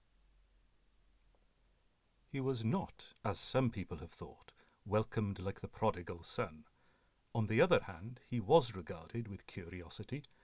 The room I’ve been using generally is our small spare room, used as a Library/box room/study, so he walls are lined with bookshelves and boxes, so seems not too echoey.
However, on plugging a microphone into the recorder, the volume immediately went up - “extmic” file attached. It’s louder, but I can not speak for its quality.